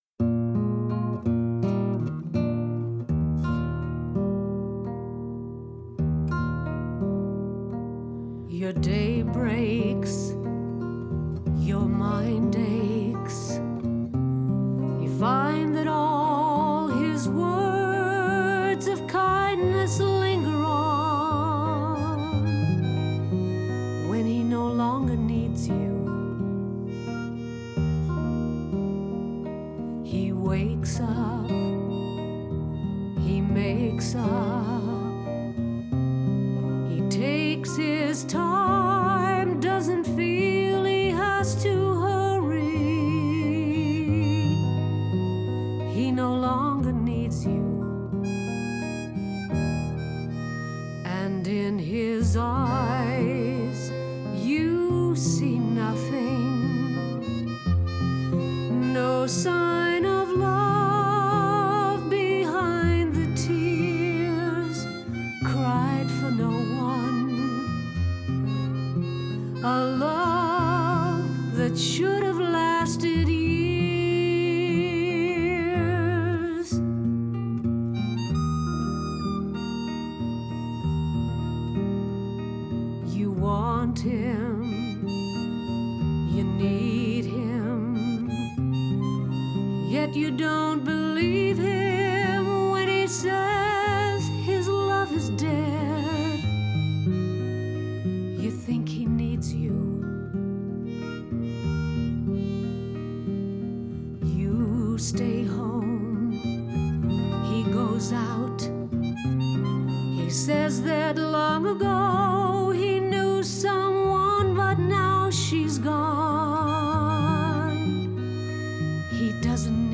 sensitively sung
guitar
harmonica